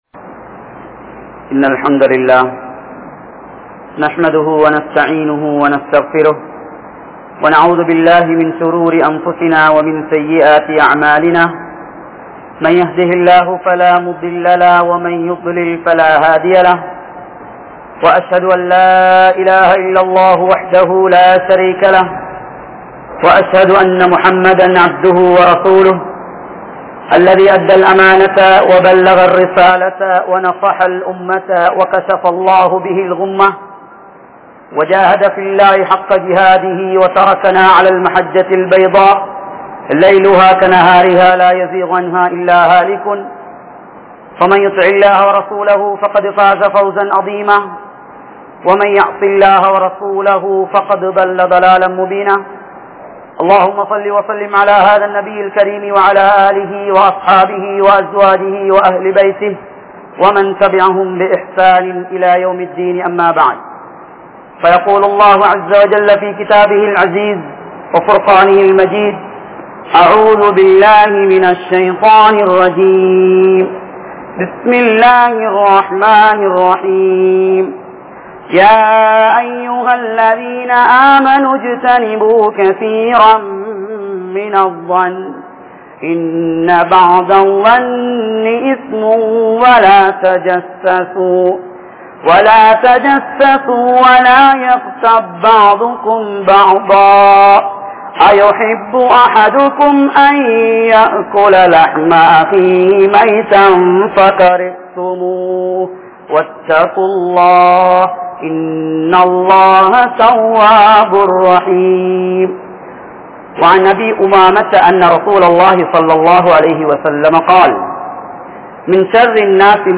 Pirar Nalan Peanugal (பிறர் நலன் பேனுங்கள்) | Audio Bayans | All Ceylon Muslim Youth Community | Addalaichenai
Hudha Jumua Masjidh